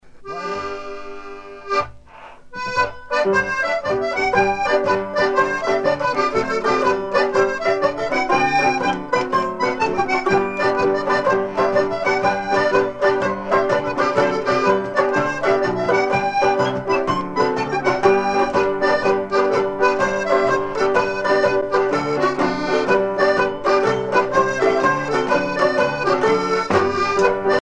Chants de marins traditionnels
Pièce musicale éditée